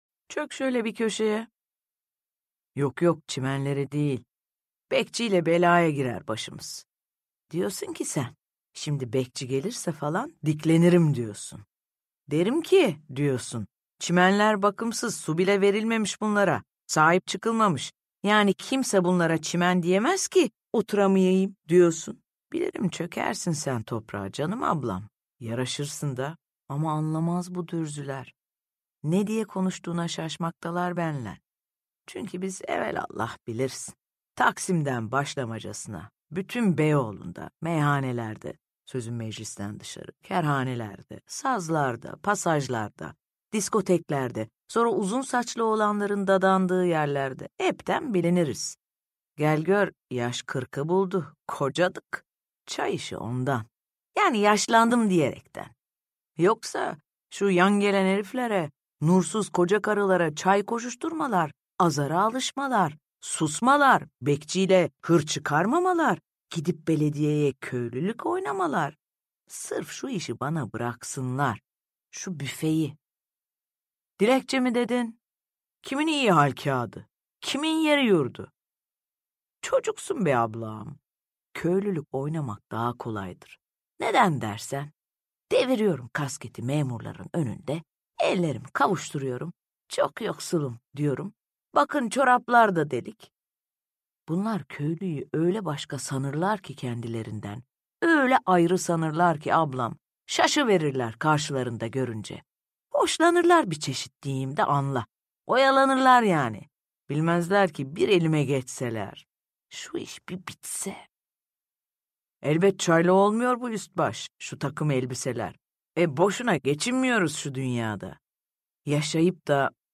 Dizboyu Papatyalar’ı Tilbe Saran’ın seslendirmesiyle dinleyebilirsiniz.
Seslendiren